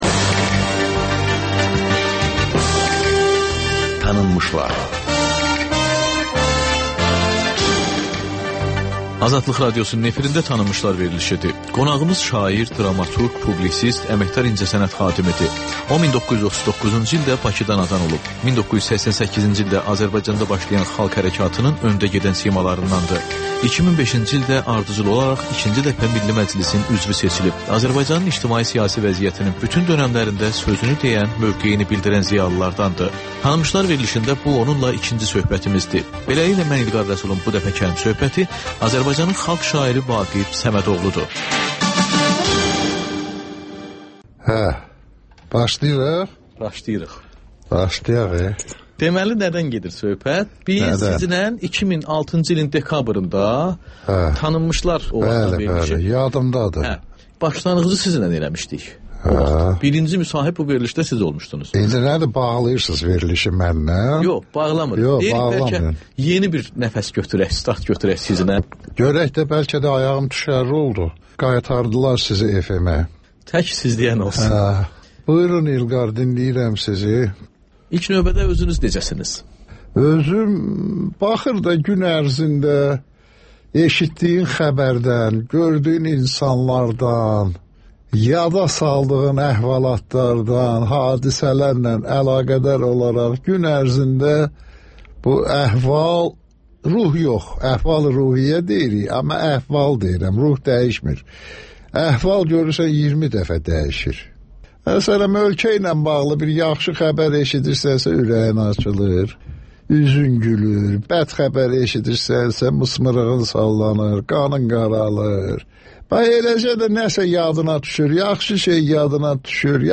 Ölkənin tanınmış simaları ilə söhbət (Təkrar)